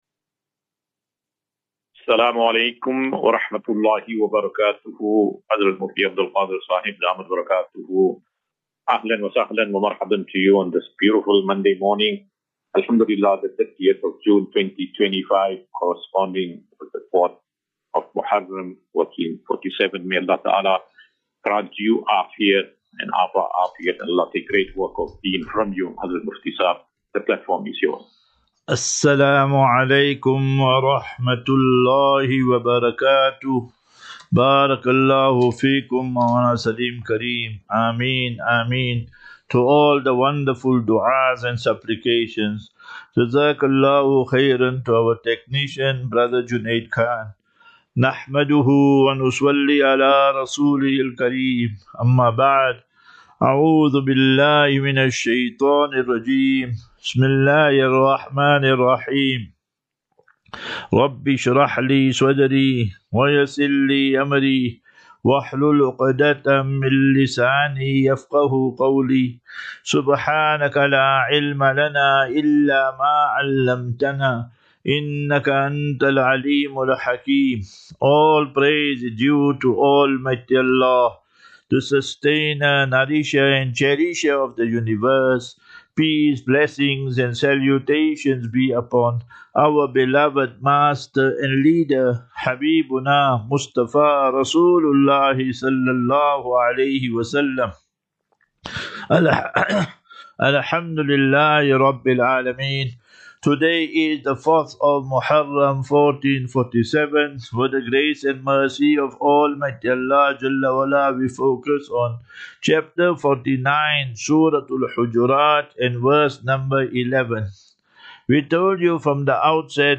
30 Jun 30 June 2025. Assafinatu - Illal - Jannah. QnA
Daily Naseeha.